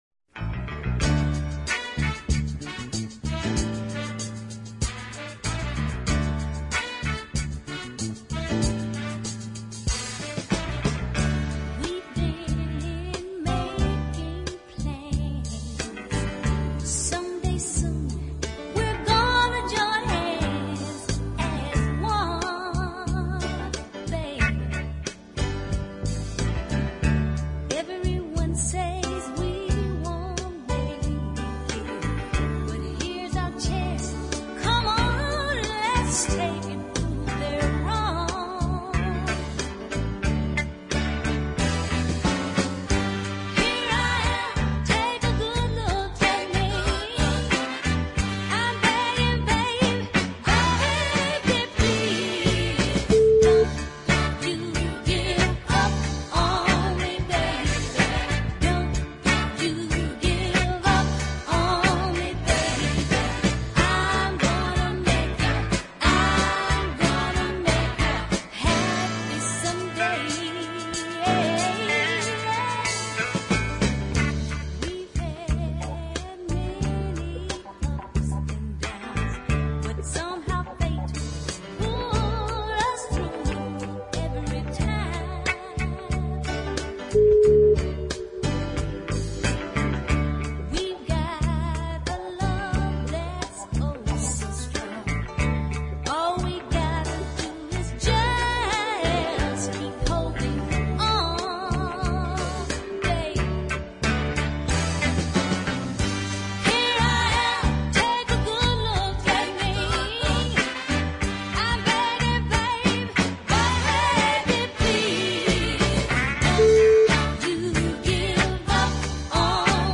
the power and commitment
southern soul